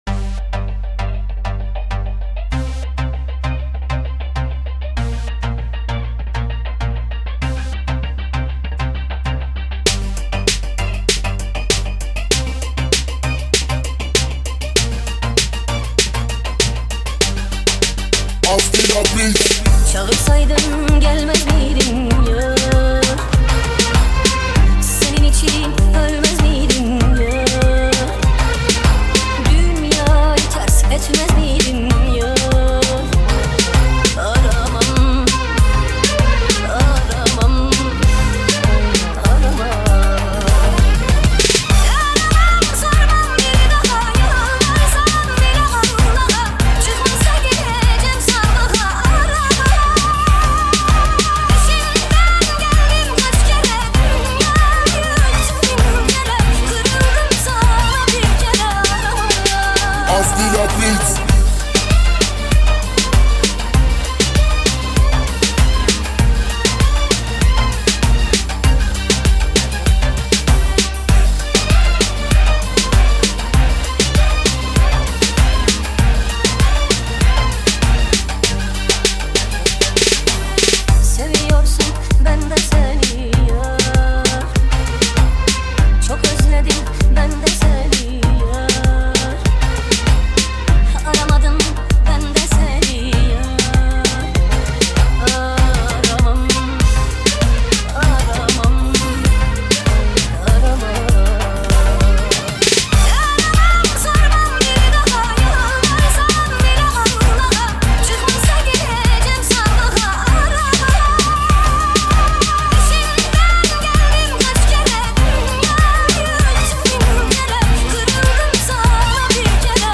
ریمیکس با صدای زن